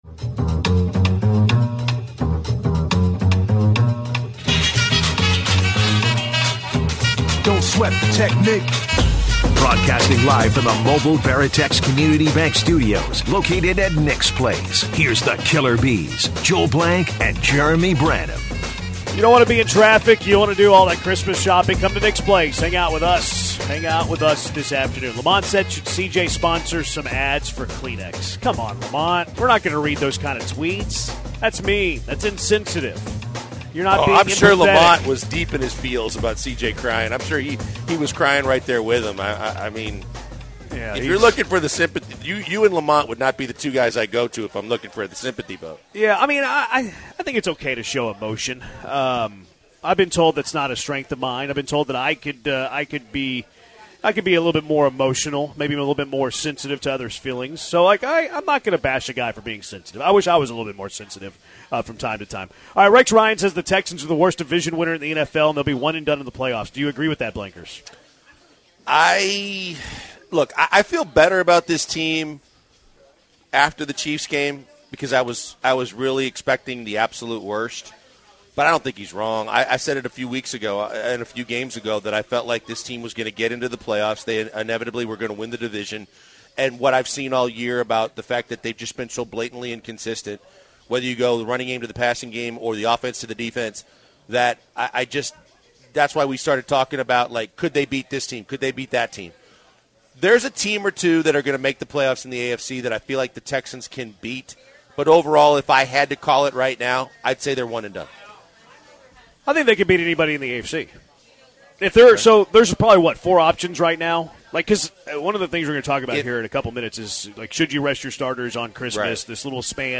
LIVE from the Christmas Party at Nick's Place!